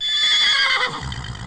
Horse Whinny 2